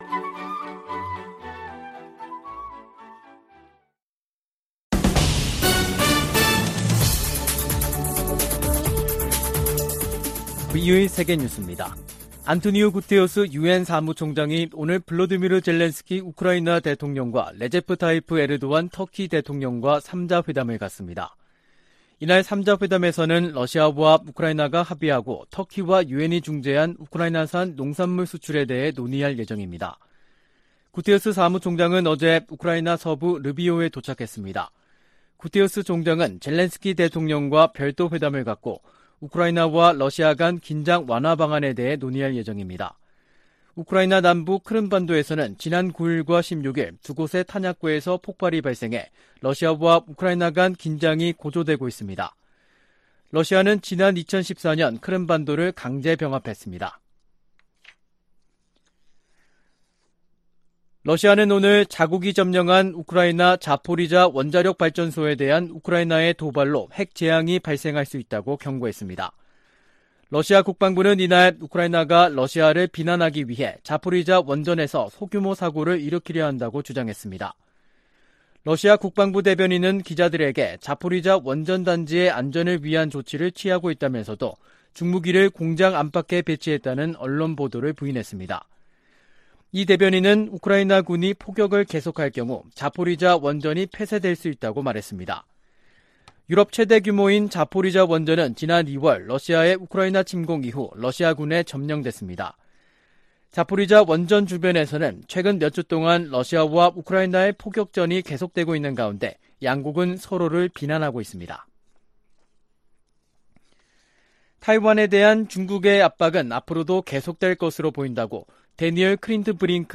세계 뉴스와 함께 미국의 모든 것을 소개하는 '생방송 여기는 워싱턴입니다', 2022년 8월 18일 아침 방송입니다. '지구촌 오늘'에서는 볼로디미르 젤렌스키 우크라이나 대통령, 레제프 타이이프 에르도안 터키('튀르키예'로 국호 변경) 대통령, 안토니우 구테흐스 유엔 사무총장이 회동하는 소식, '아메리카 나우'에서는 마이크 펜스 전 부통령이 의사당 난입 사태를 조사하는 하원 특위에서 증언을 고려한다는 이야기 전해드립니다.